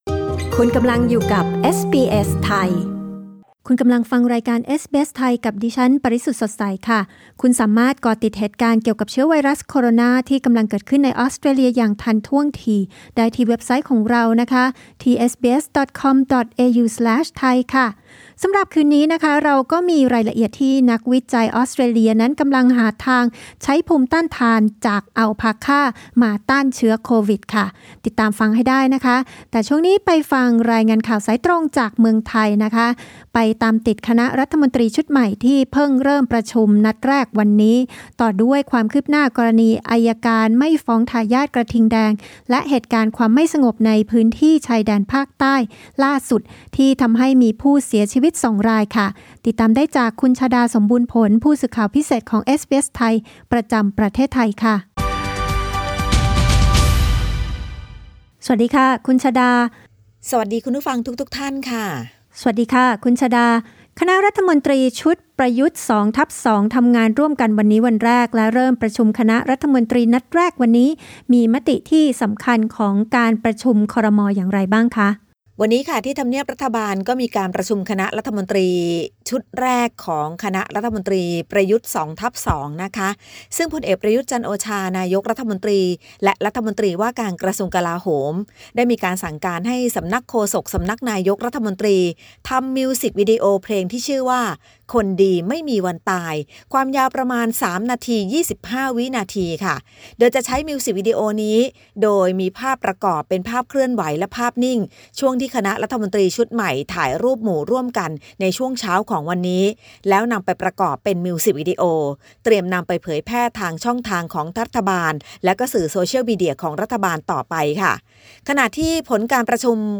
รายงานข่าวสายตรงจากเมืองไทย จากเอสบีเอส ไทย Source: Pixabay
thai_news_report_aug_13_podcast.mp3